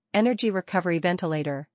eh · nr · jee - ruh · kuh · vr · ee - ven · tuh · lay · tr